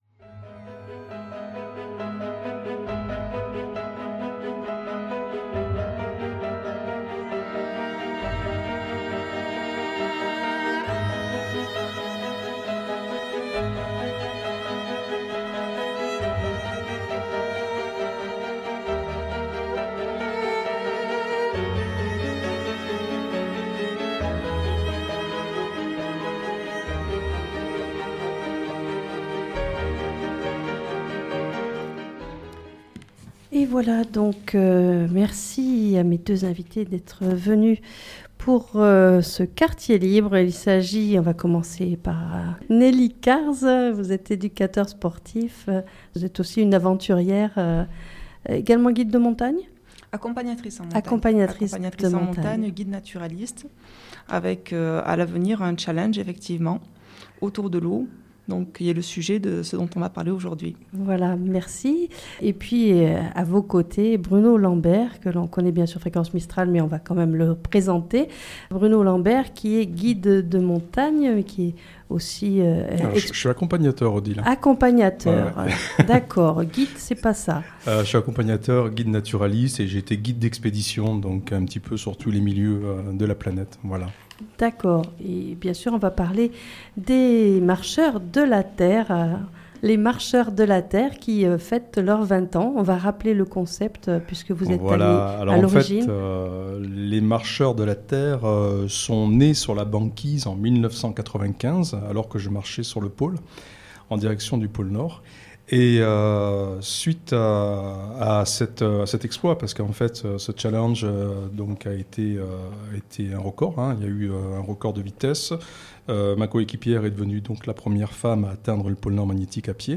Ils sont les invités de l'émission Quartier Libre et répondent aux questions